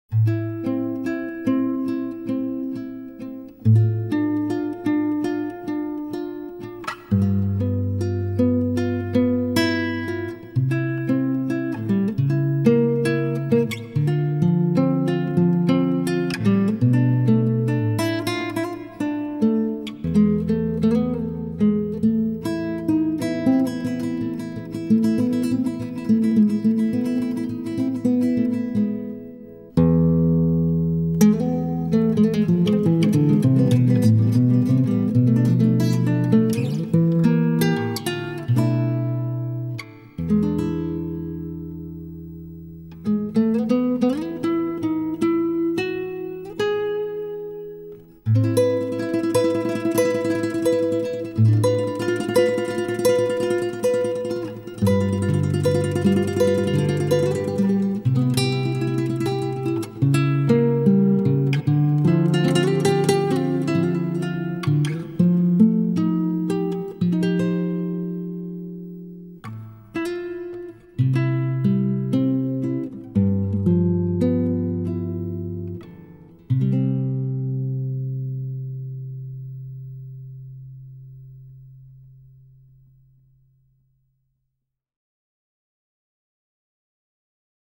总是喜欢钢琴的 黑白琴键